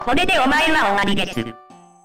koredeoqian hazhong waridesu Meme Sound Effect
koredeoqian hazhong waridesu.mp3